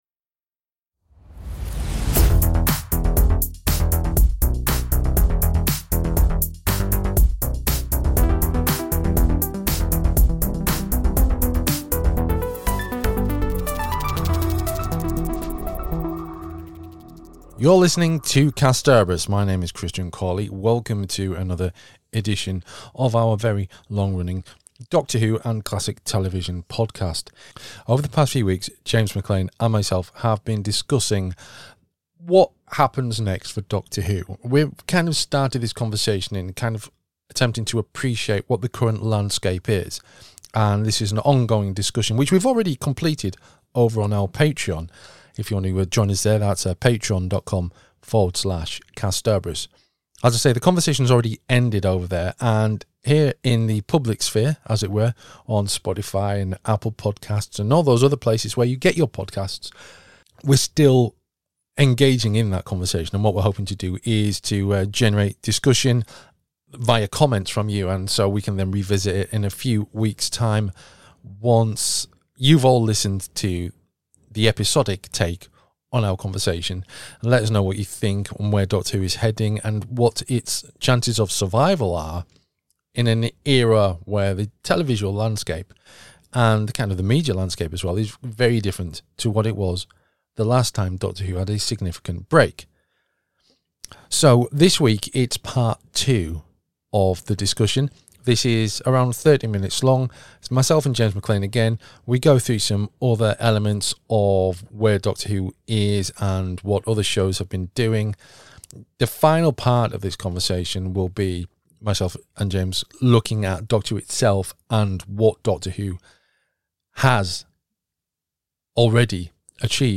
in-depth discussion